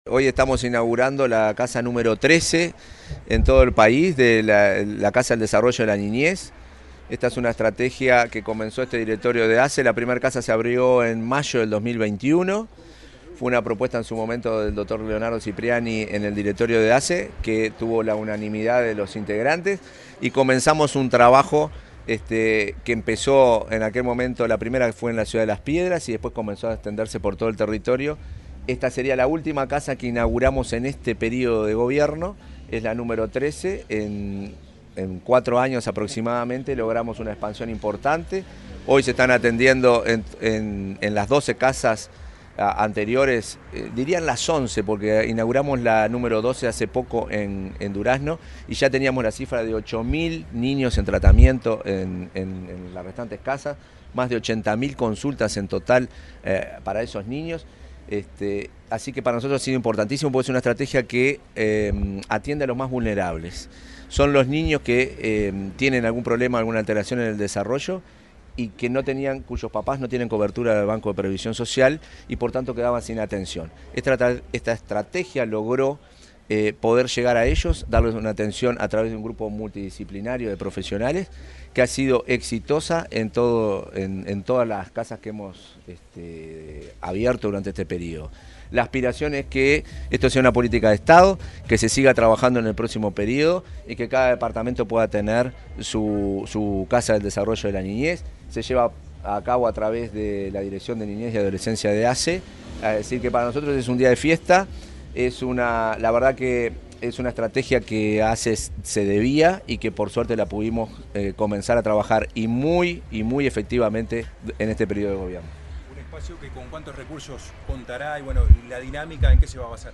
Declaraciones del presidente de ASSE, Marcelo Sosa
El presidente de la Administración de los Servicios de Salud del Estado (ASSE), Marcelo Sosa, dialogó con la prensa, luego de inaugurar una casa de